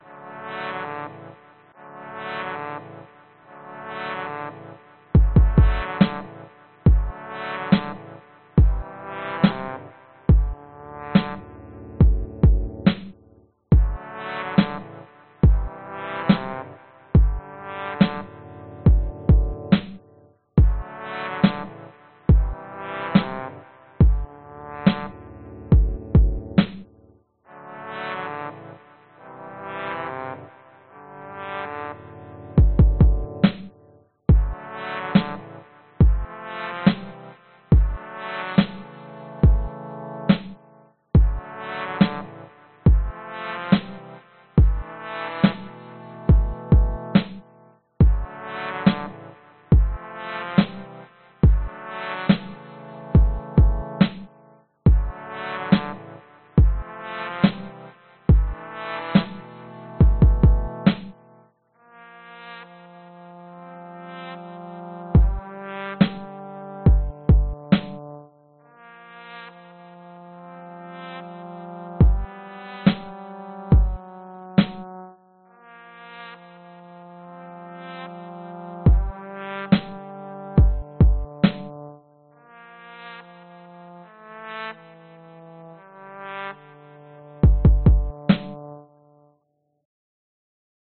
高音小号膨胀
描述：我在dsk brass vst的设置上做了手脚，得到了一些有趣的小号声音。
Tag: 节拍 铜管 DSK 电子 踢球 Linux lmms 循环 小号 ubuntu vst